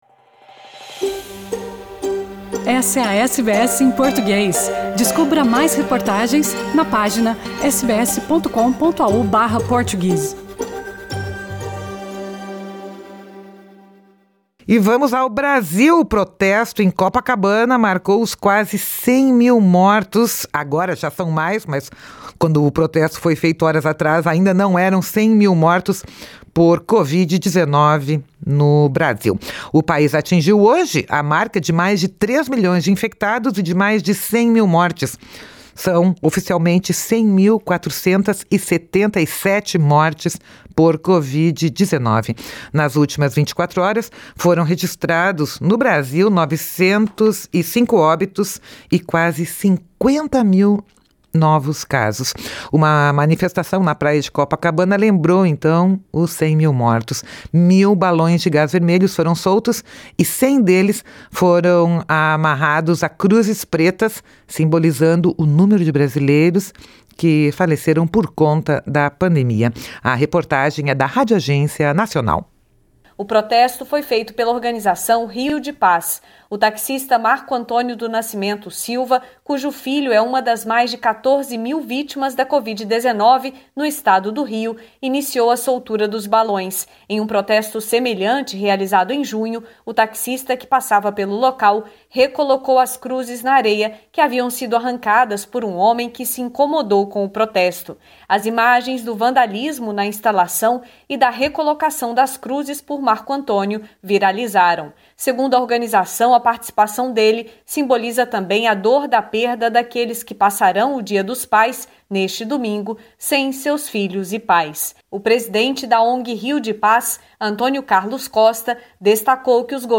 O Brasil atingiu hoje a marca de mais de 3 milhões de infectados e de mais de 100 mil mortes (100.477) por coronavírus. Este podcast traz a reportagem da Radioagência Nacional.